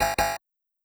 Error4.wav